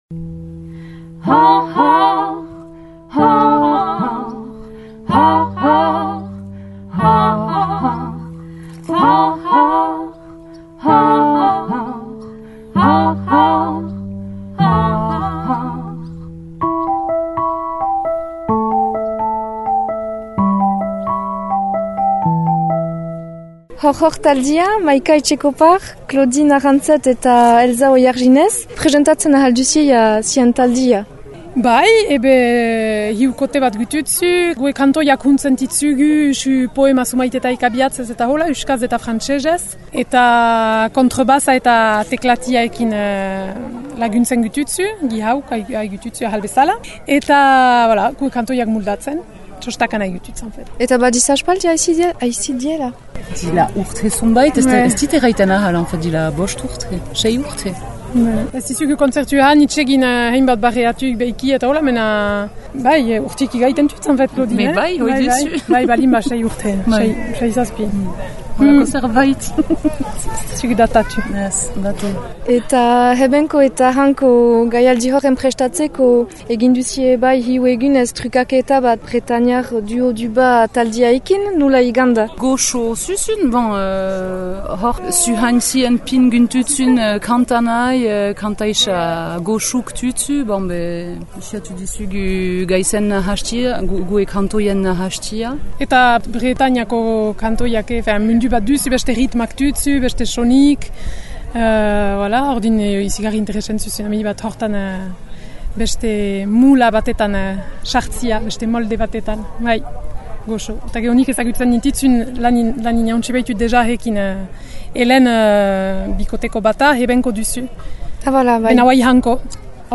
Erreportajea :